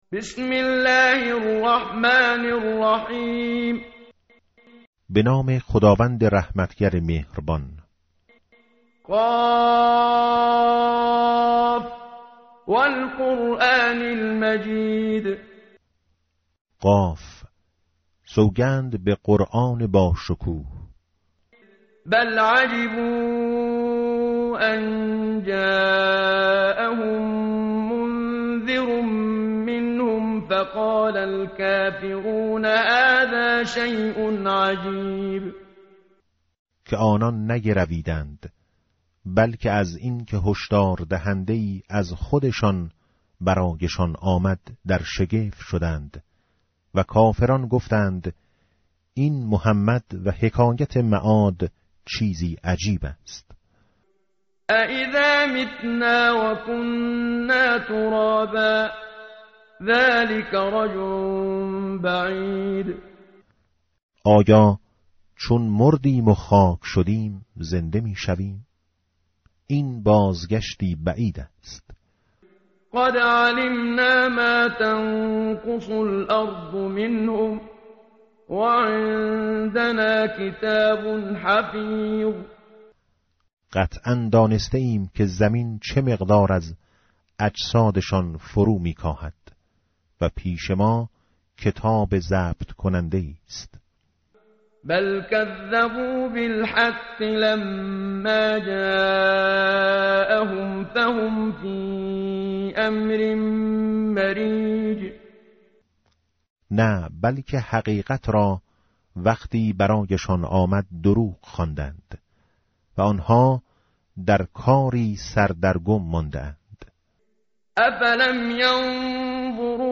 tartil_menshavi va tarjome_Page_518.mp3